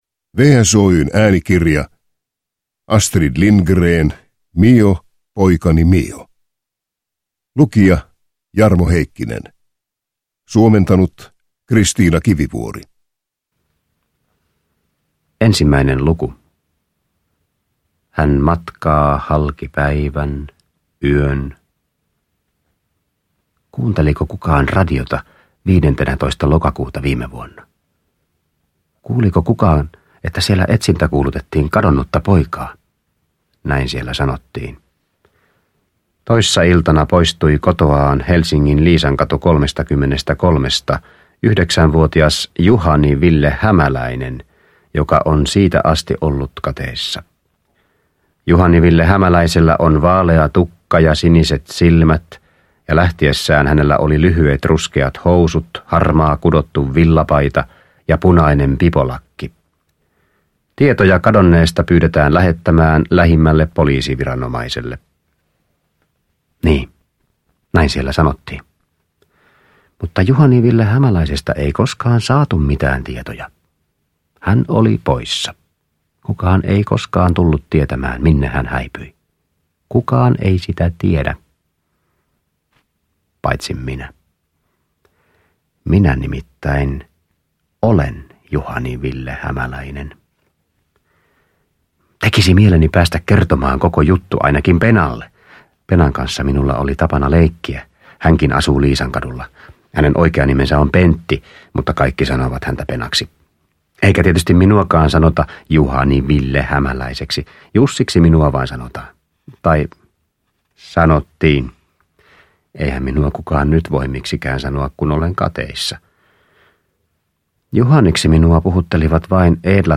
Mio, poikani Mio – Ljudbok – Laddas ner